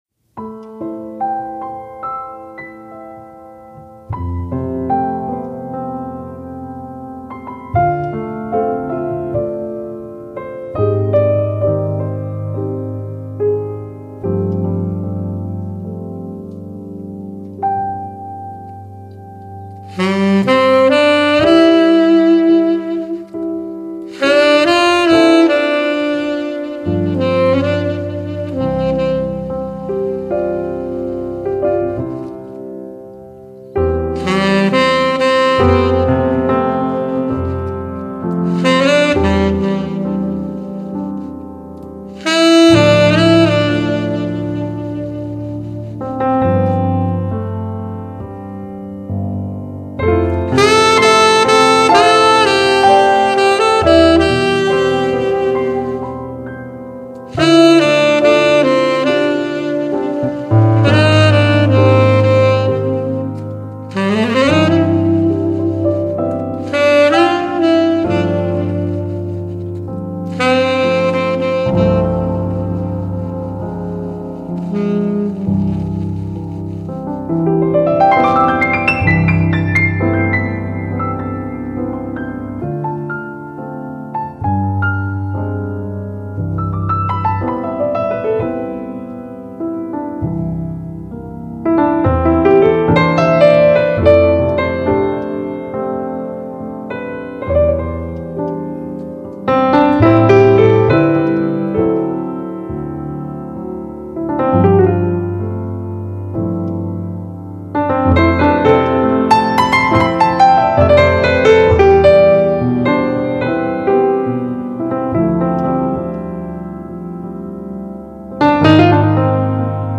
爵士邂逅经典，碰撞交融如初夏的记忆，亦如苦艾与甜茴香相融于酒盏！